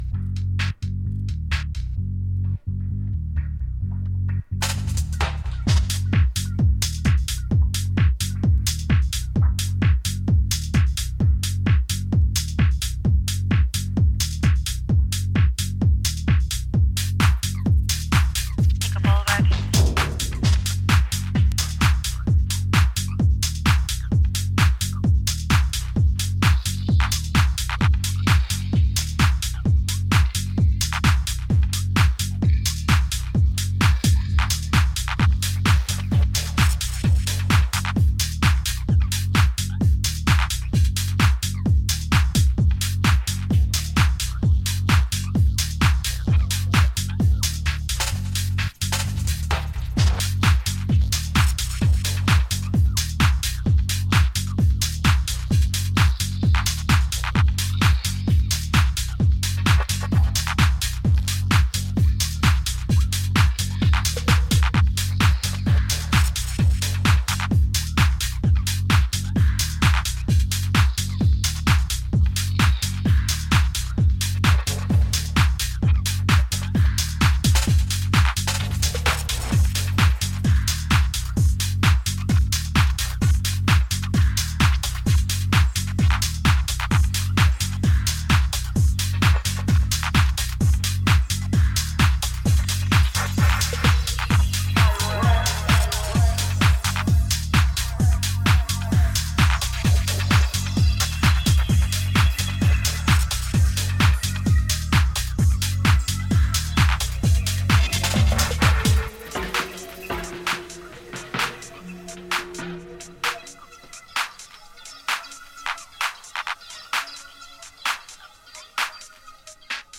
いずれの楽曲も当レーベルらしいカラーの、ソリッドかつ極めて圧の強いミニマル・ハウス！